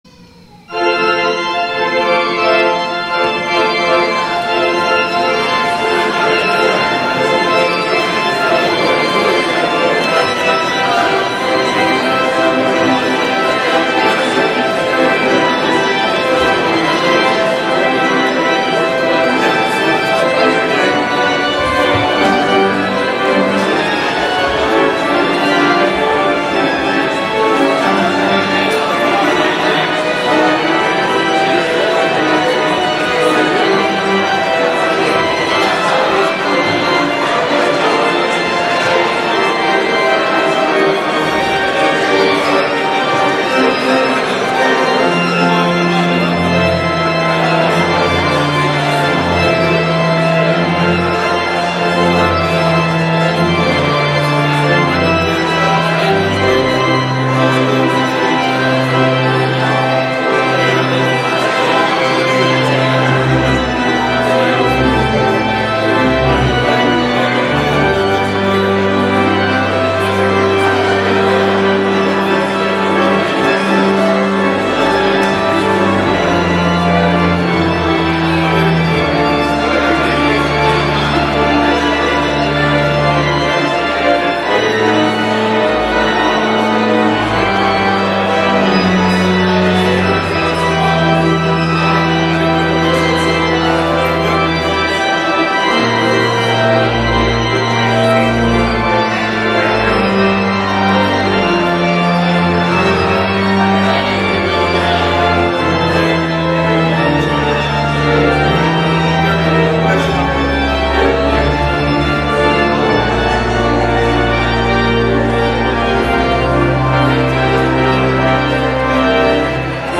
Easter Sunday
*THE POSTLUDE